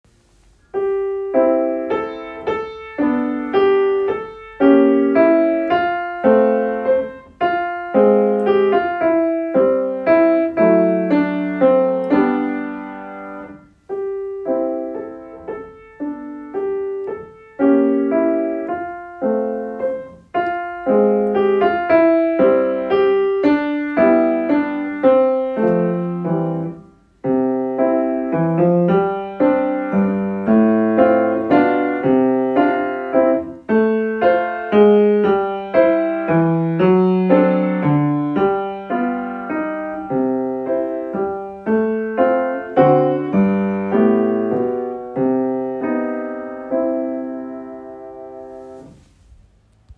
Pour piano solo Degré cycle 1